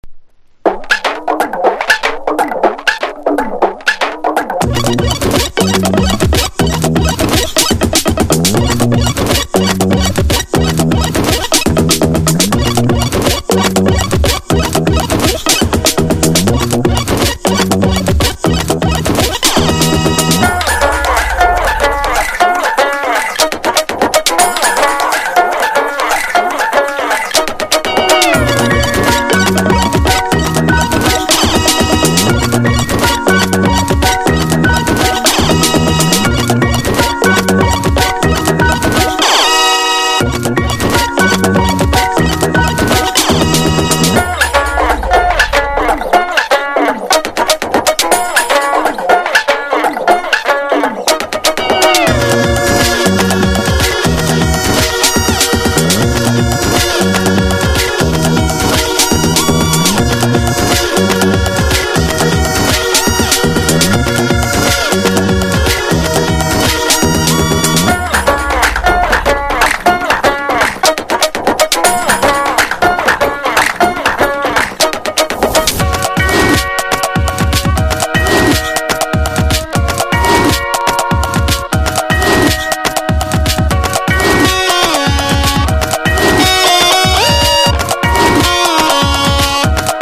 INDIE DANCE